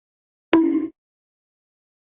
bonk.wav